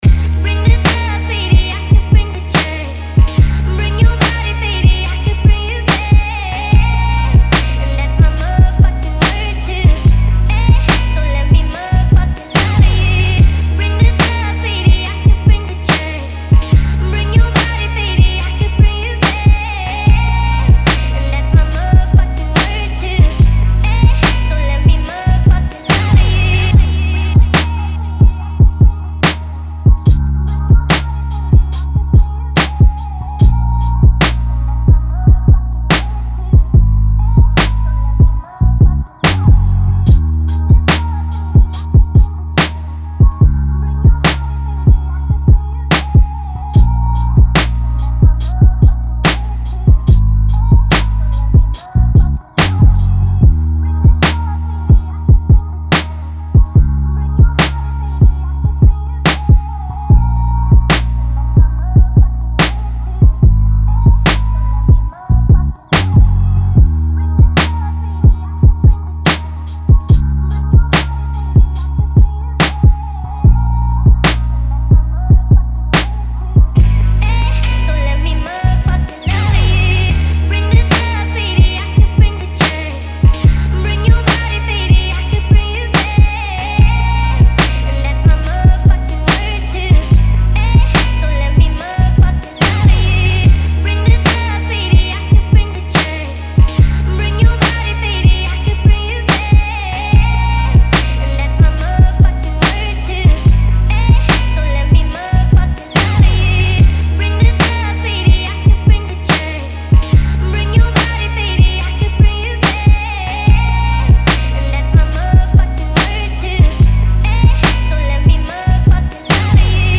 БАСЫ в МАШИНУ